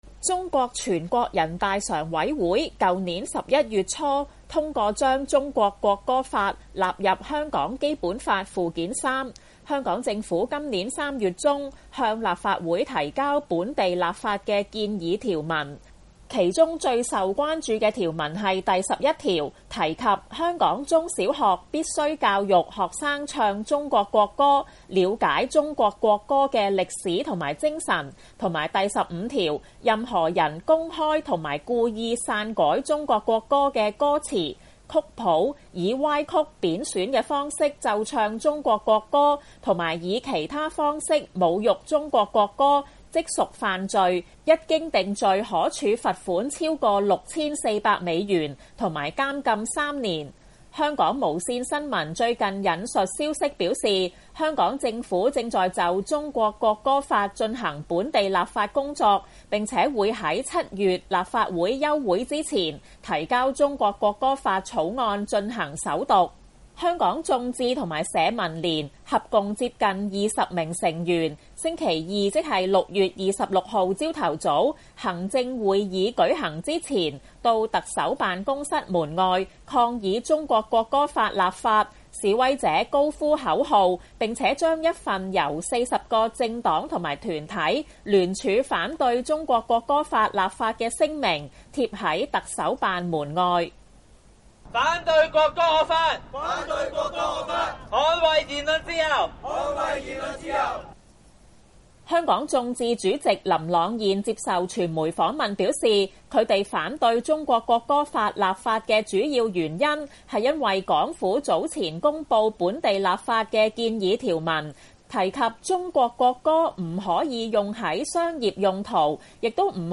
示威者高呼口號：反對國歌惡法，捍衛言論自由。